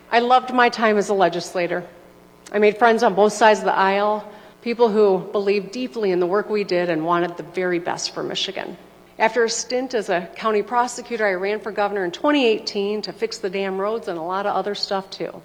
AUDIO: Governor Whitmer delivers her final State of the State address
LANSING, MI (WKZO AM/FM) – Governor Gretchen Whitmer has delivered her eighth and final State of the State address to a joint session of the Michigan Legislature.